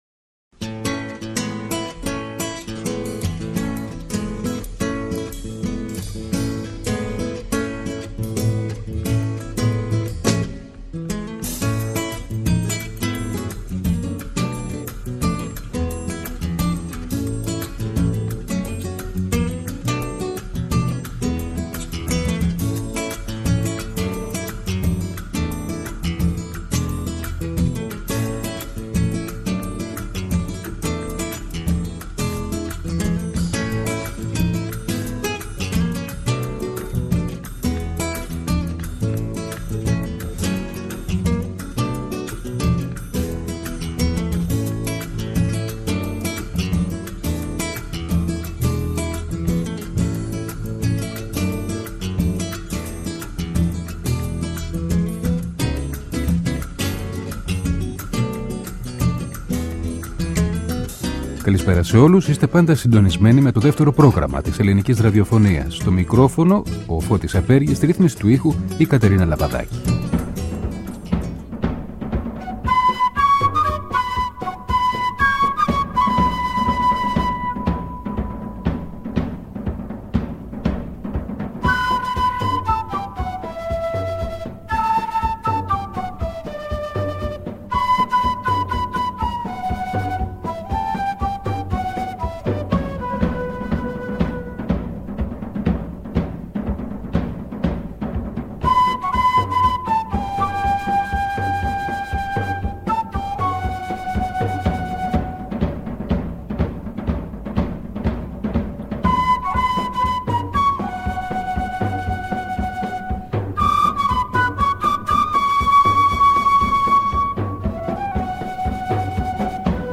Ο Κώστας Γαβράς σε μια συνέντευξη- εξομολόγηση, που μεταδόθηκε σε δυο μέρη: τη Δευτέρα 10 και την Τρίτη 11 Δεκεμβρίου από το Δεύτερο Πρόγραμμα.